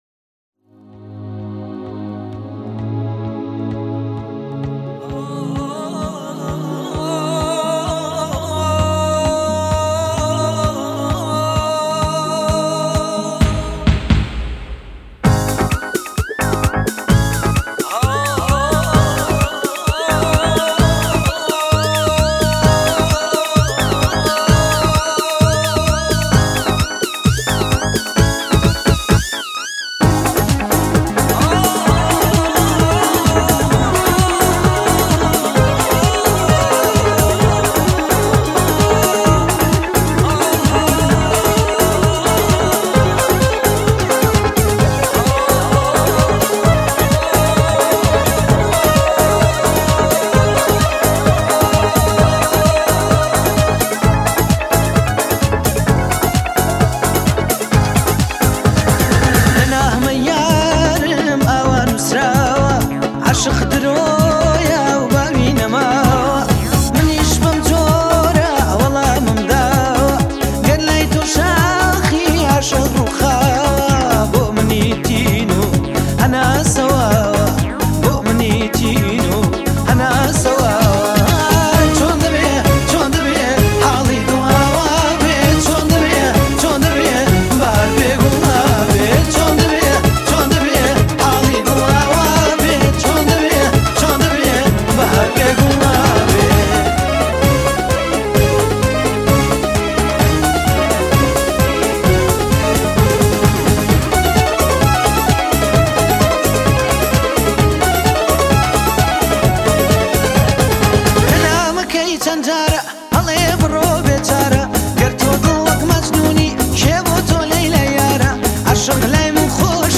دانلود آهنگ کردی